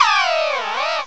cry_not_komala.aif